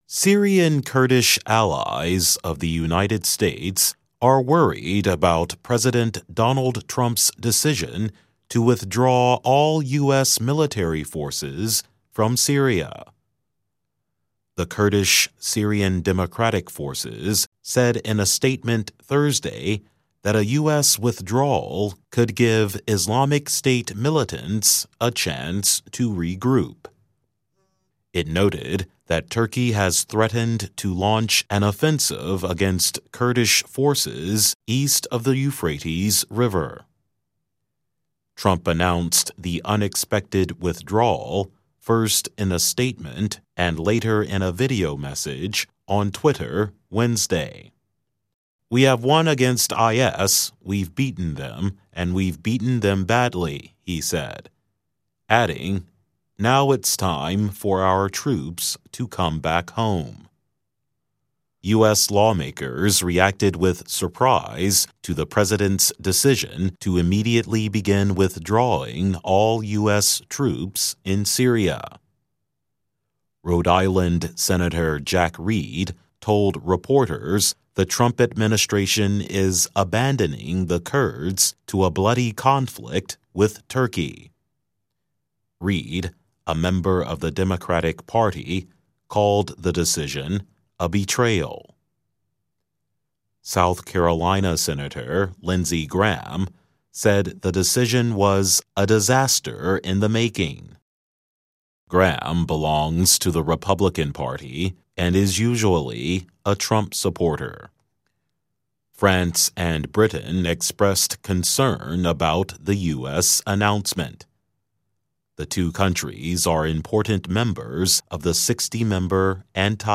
VOA News report